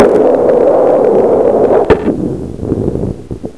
1 channel
snd_26752_roll.wav